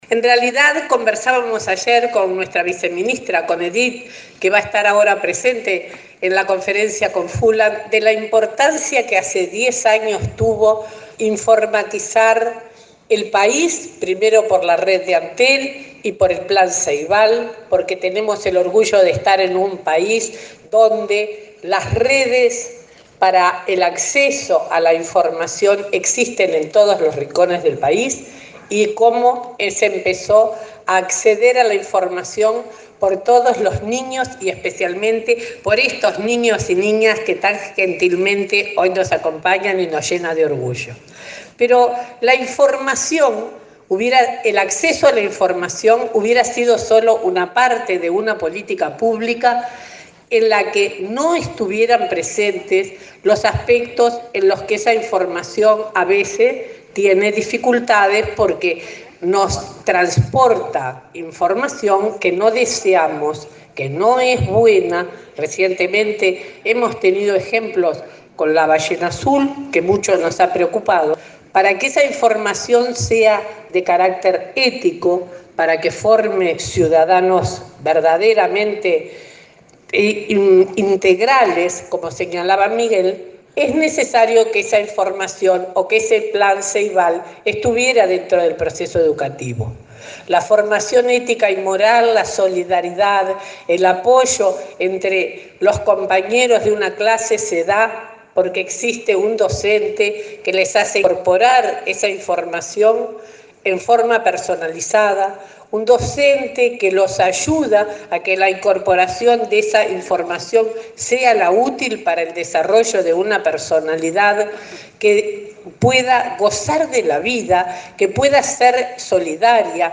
La ministra de Educación y Cultura, María Julia Muñoz, destacó la decisión del Gobierno, hace 10 años, de informatizar el país con la red de Antel y el Plan Ceibal. Durante la apertura del Foro Internacional de Educación y Tecnología, valoró la importancia de esta política pública integral que sumó a los docentes y dijo que es necesaria una formación ética y moral para el desarrollo de ciudadanos integrales.